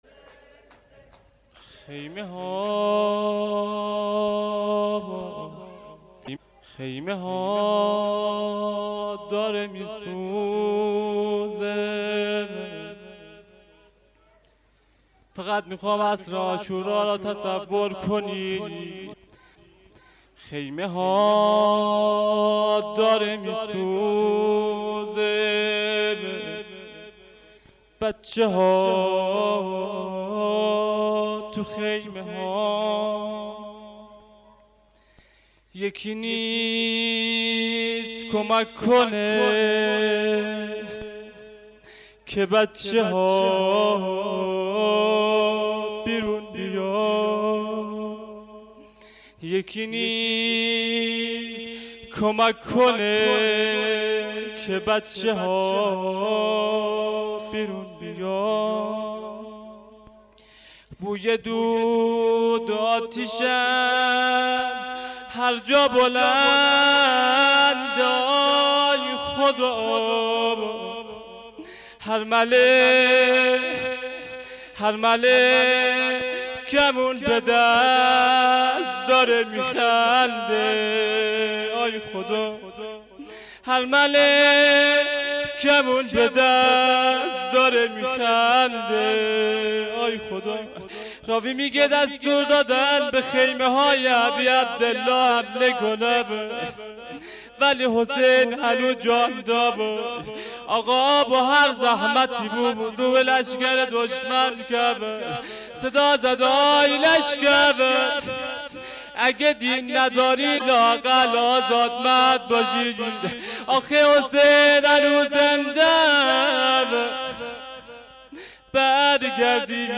شب-11-محرم-93-هیآت-ثارالله-3.mp3